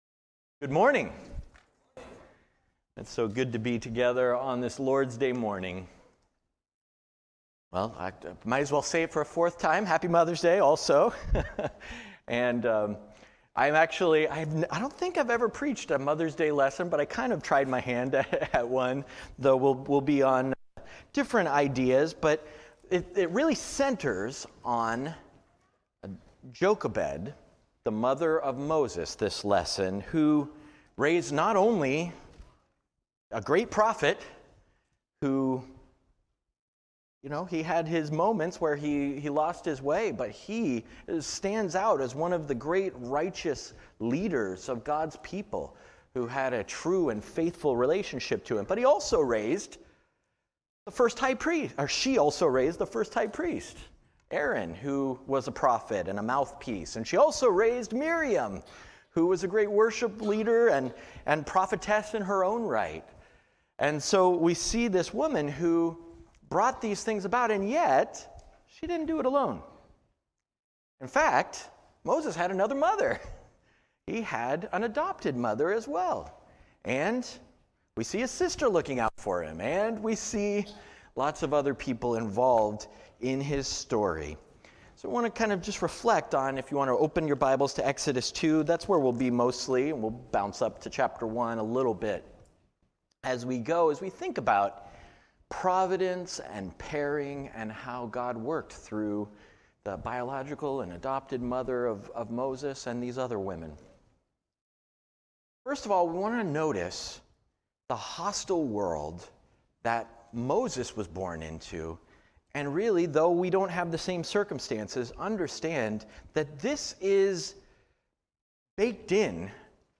Providence & Parenting — A Mother’s Day Sermon – North church of Christ